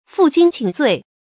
fù jīng qǐng zuì
负荆请罪发音
成语正音荆，不能读作“jīn”。